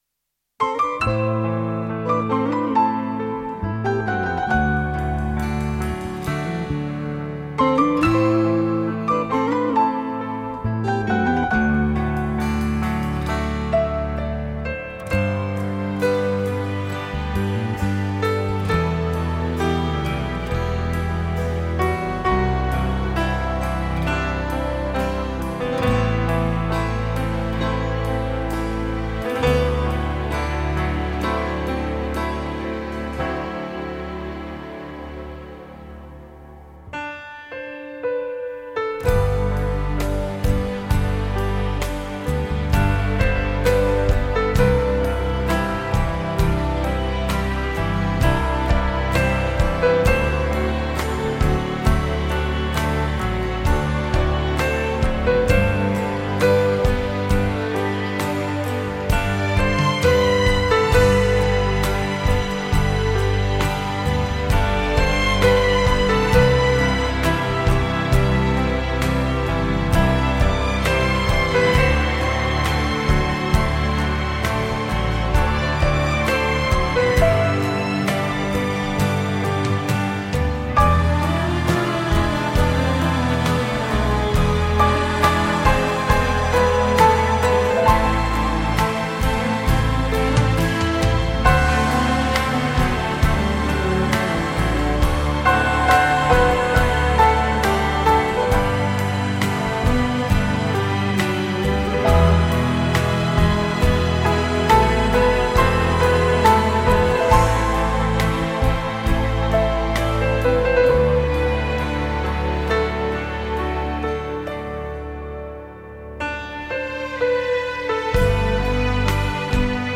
radiomarelamaddalena / STRUMENTALE / PIANO /